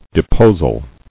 [de·pos·al]